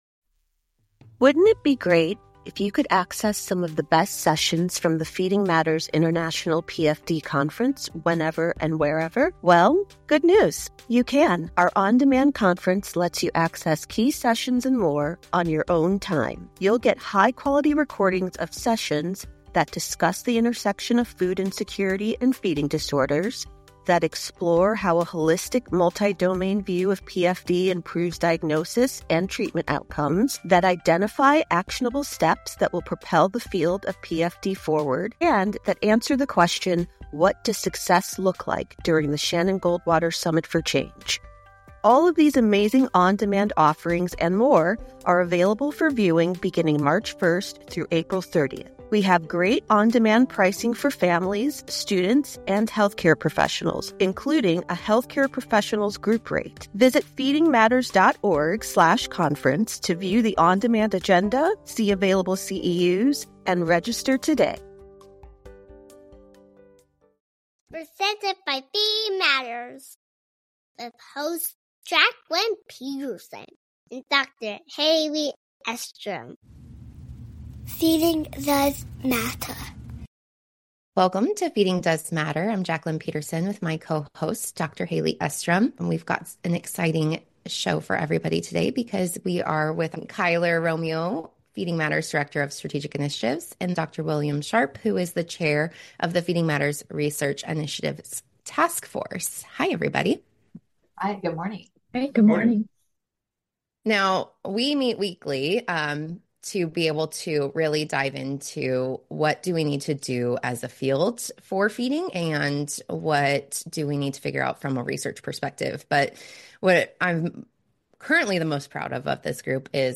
In this episode of Feeding Does Matter, four of the authors (including our hosts) of the consensus paper on the diagnostic overlap and distinction for pediatric feeding disorder and Avoidant/Restrictive Food Intake Disorder discuss their thoughts on the 10 consensus statements, the importance of accurate diagnosis, and the need for collaboration and a common language in the field.